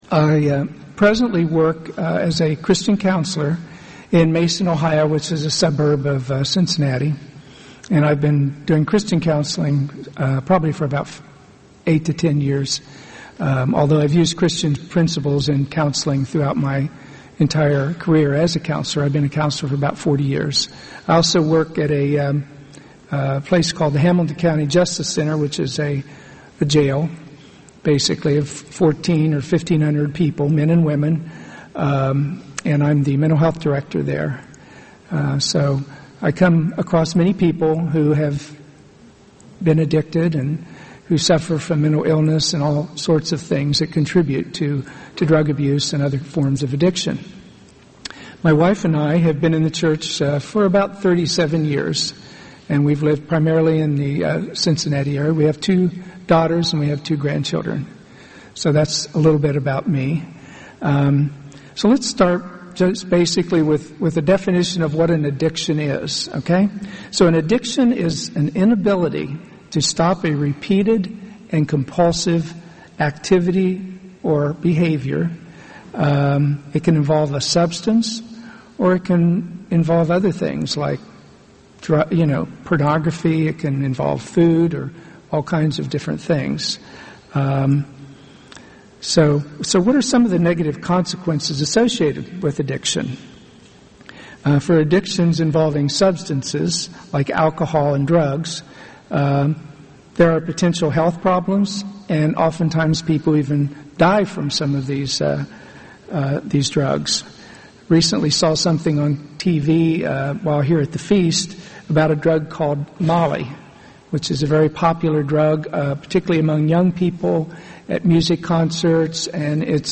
Seminar on Addictions
This sermon was given at the Jekyll Island, Georgia 2013 Feast site.